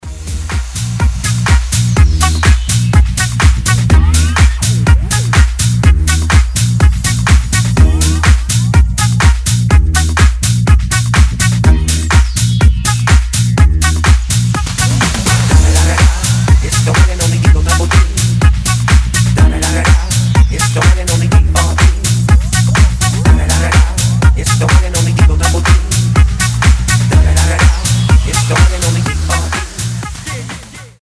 • Качество: 320, Stereo
ритмичные
retromix
веселые
house
динамичные
ремиксы